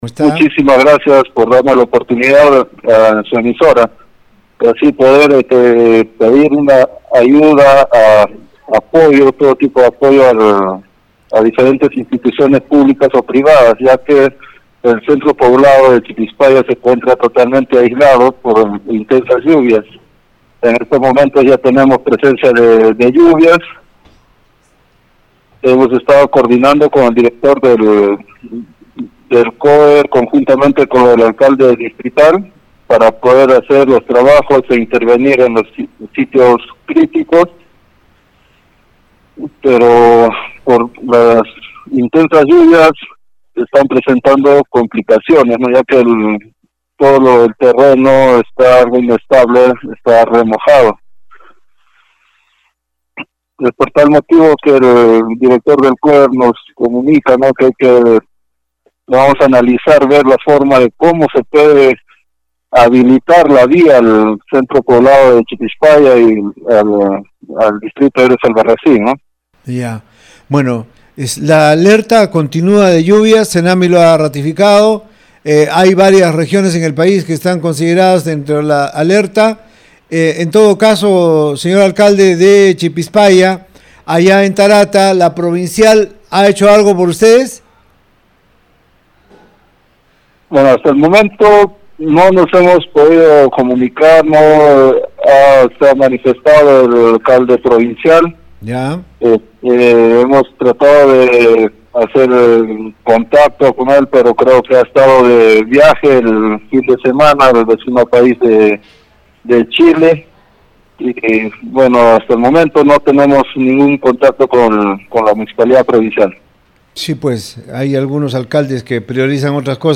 Ivan Flores Ticona, alcalde del centro poblado Chipispaya (distrito Héroes Albarracín Chucatamani, provincia Tarata), vía Radio Uno pidió ayuda a instituciones públicas y privadas, afirmando se encuentran «totalmente aislados debido a intensas lluvias».